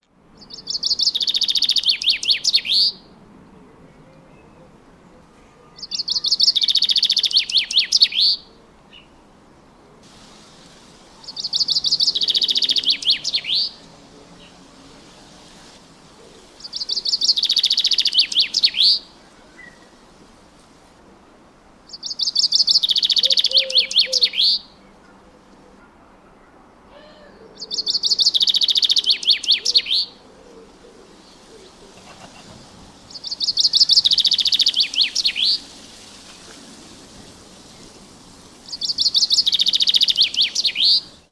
Over the last few days, while the Blackbird has become less vocal, another bird has taken to using the conifer tops as a stage - the male Chaffinch.
However, this afternoon he was more cooperative for a couple of minutes, as were the aircraft that didn't fly overhead, the traffic that didn't pass the house, and the neighbours who didn't start mowing their lawns ready for the 'big wedding day'!
Unlike the songs of the usual accomplished vocalists that sing at the bottom of the garden (Blackbird and Robin) that of the Chaffinch consists of a short burst of several repeated notes followed by a silence which in this case lasted between 10 and 17 seconds.
Chaffinch song sequences, 28 April